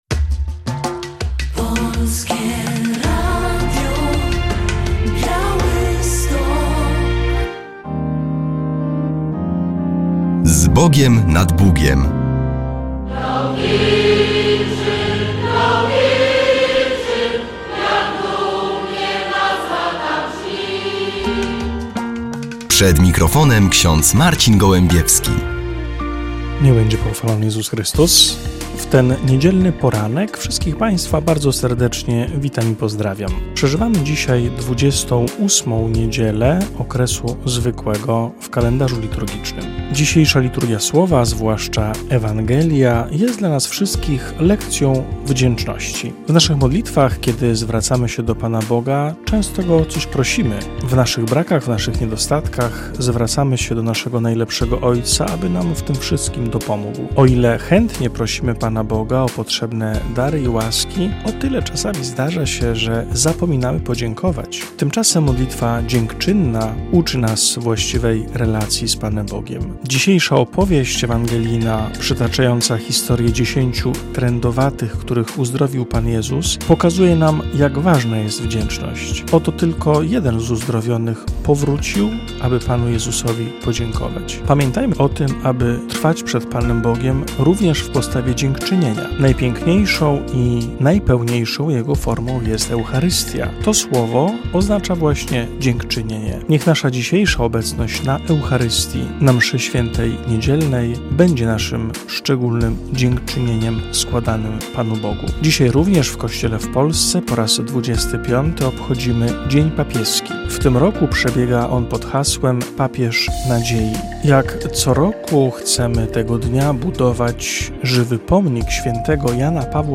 W audycji relacja z jubileuszu 50-lecia pożycia małżeńskiego małżonków z gminy Drohiczyn.